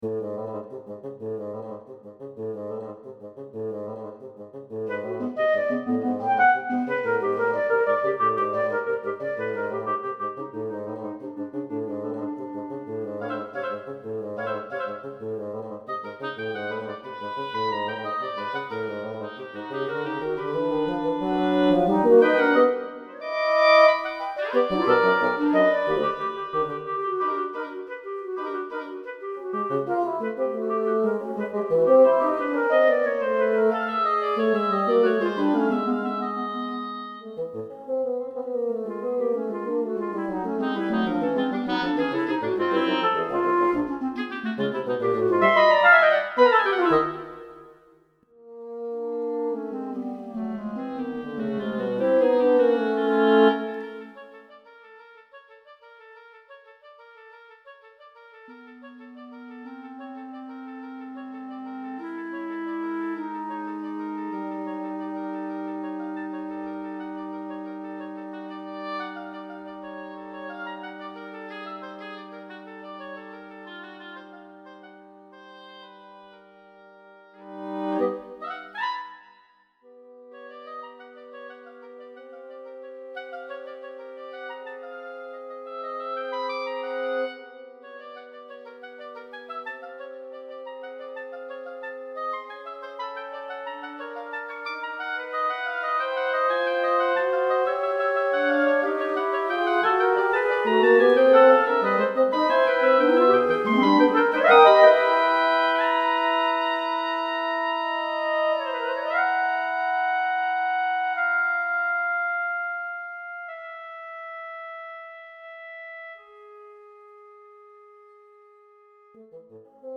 Oboe
Clarinet
Bassoon